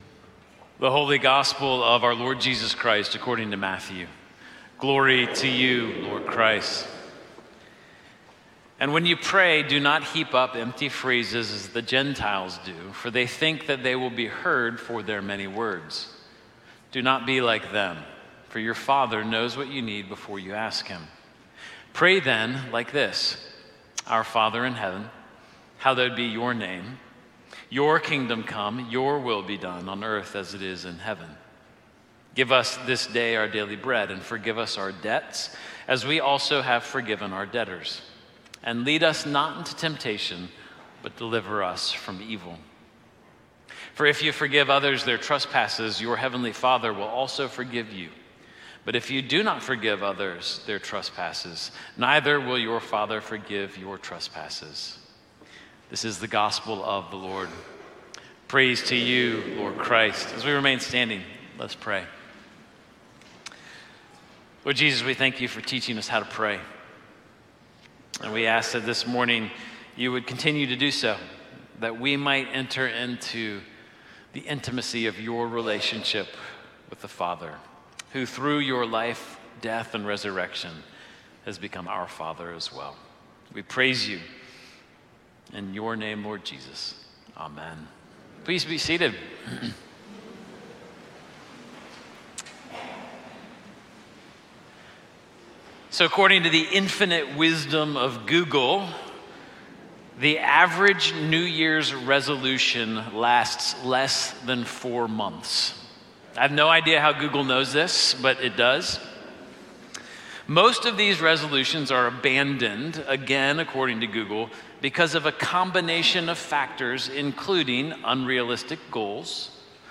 Sermon-January-4-2026.mp3